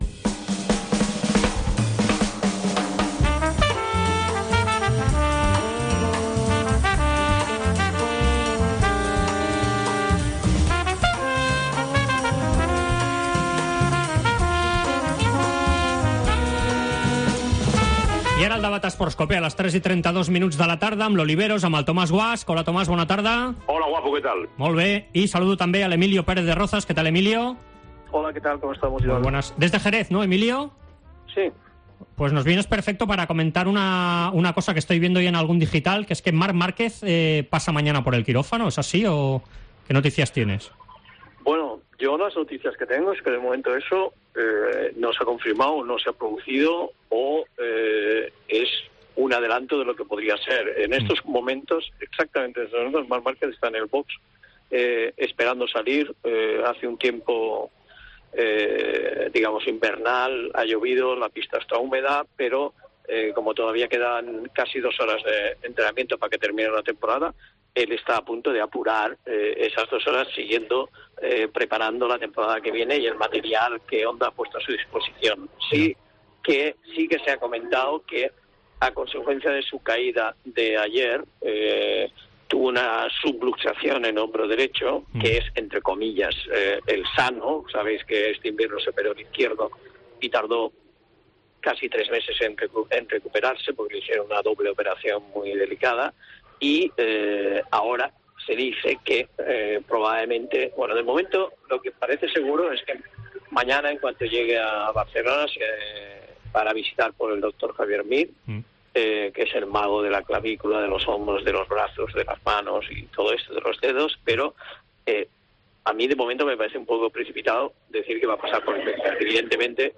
AUDIO: El debat dels dimarts amb els grans Tomás Guasch i Emilio Pérez de Rozas